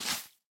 assets / minecraft / sounds / block / moss / step3.ogg
step3.ogg